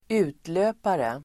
Ladda ner uttalet
utlöpare substantiv (bildligt " sidogren"), offshoot [also used figuratively] Uttal: [²'u:tlö:pare]